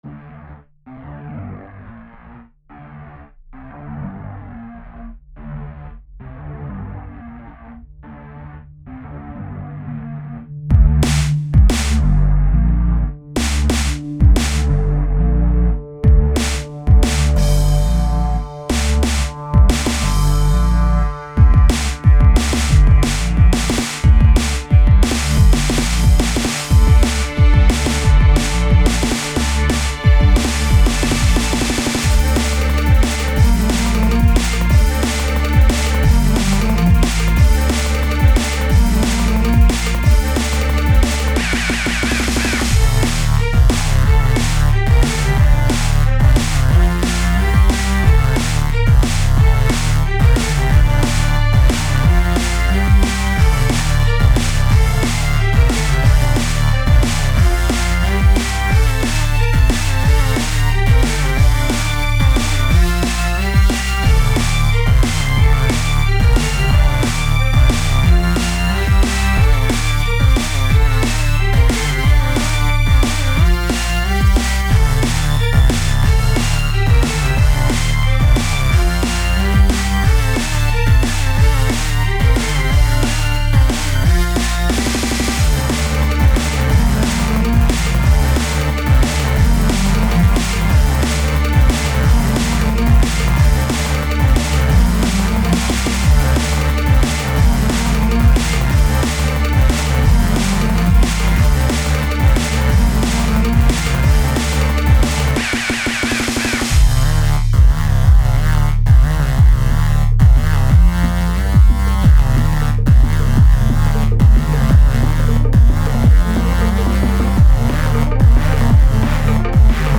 where repetitive rhythms require raw and violent sounds
dnb